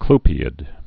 (klpē-ĭd)